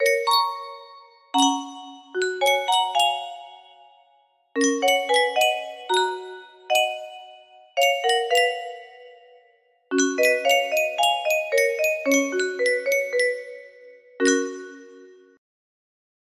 Yunsheng Music Box - Debussy Clair de Lune Y572 music box melody
Full range 60